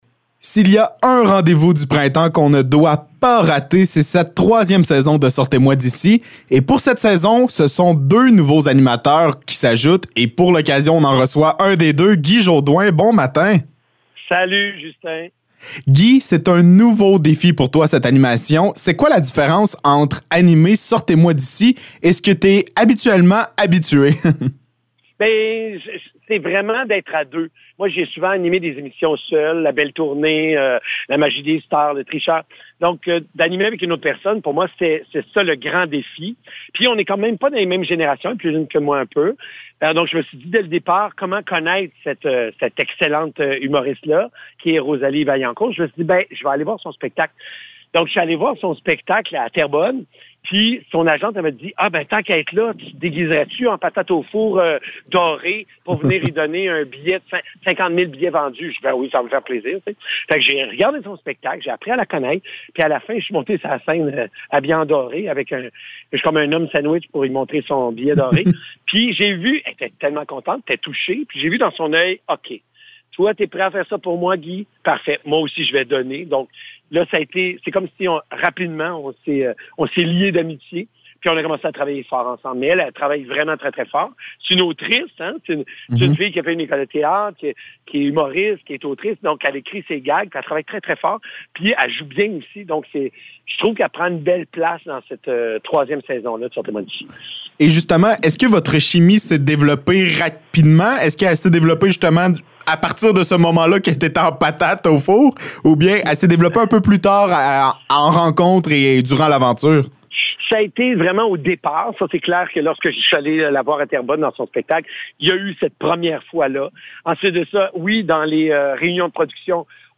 Entrevue avec Guy Jodoin
Entrevue avec le nouvel animateur de Sortez-moi d’ici, Guy Jodoin, concernant la nouvelle saison de l’émission.
ENTREVUE-GUY-JODOIN-1.mp3